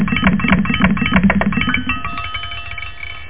Amiga 8-bit Sampled Voice
klicker.mp3